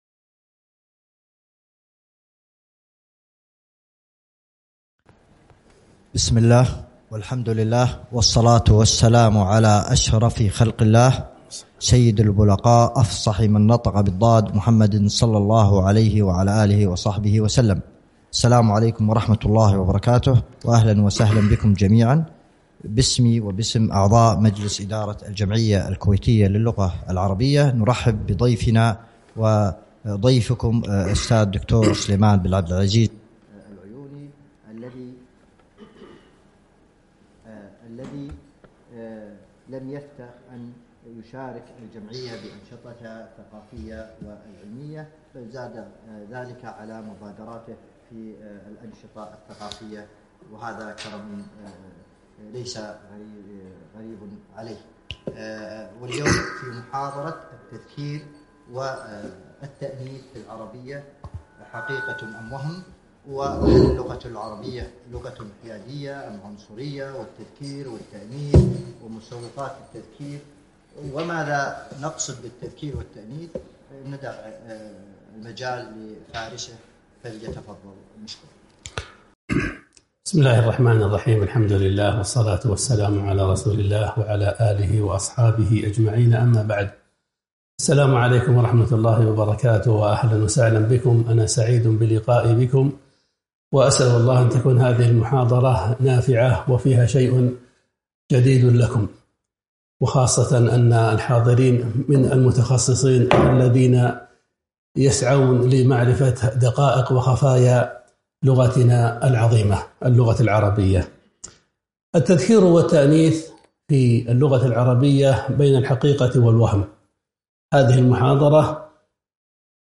محاضرة - التذكير والتأنيث في العربية بين الحقيقة والوهم